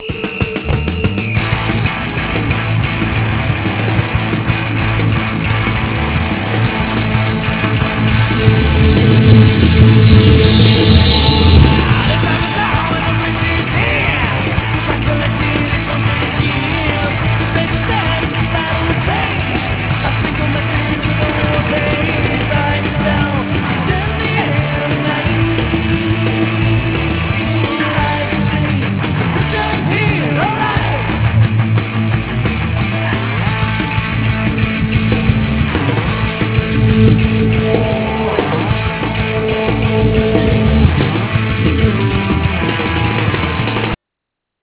Kickbox originated from an upbeat, dance music-type riff the lead guitarist
After we added drums, bass, rhythm guitar, and some preliminary keyboard parts
The samples were added to the CMP ("cool middle part") during the recording session.